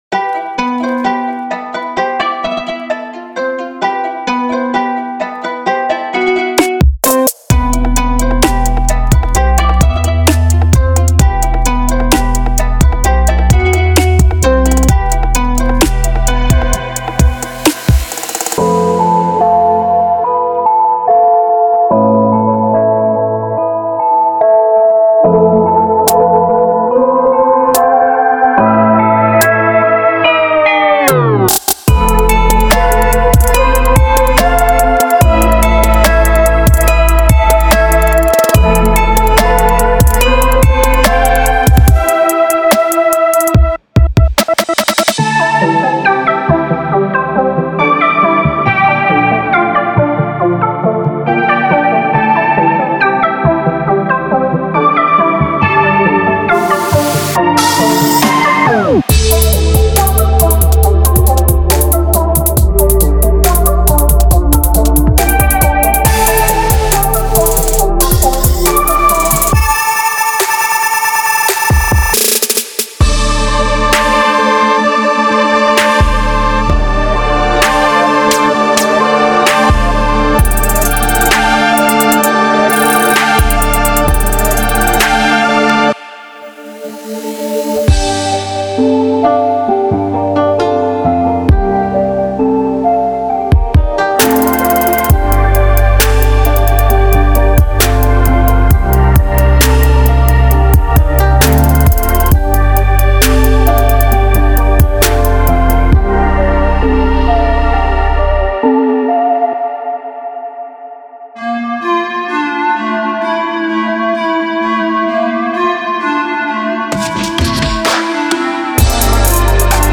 Genre:Trap
収録されているのは、ダークでウェイビー、そして感情豊かなサウンドの数々。
デモサウンドはコチラ↓